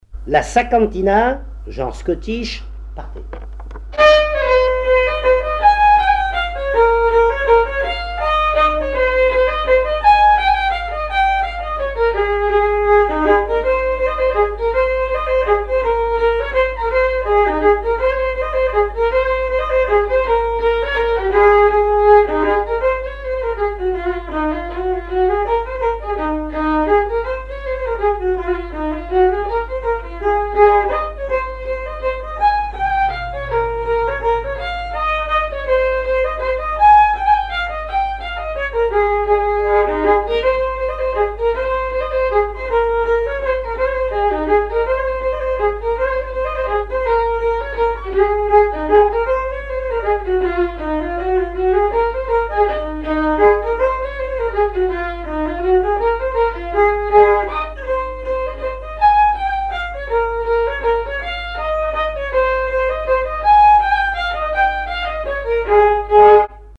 Mémoires et Patrimoines vivants - RaddO est une base de données d'archives iconographiques et sonores.
Chants brefs - A danser
danse : scottich trois pas
recherche de répertoire de violon pour le groupe folklorique
Pièce musicale inédite